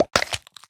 Minecraft Version Minecraft Version latest Latest Release | Latest Snapshot latest / assets / minecraft / sounds / mob / turtle / egg / drop_egg1.ogg Compare With Compare With Latest Release | Latest Snapshot
drop_egg1.ogg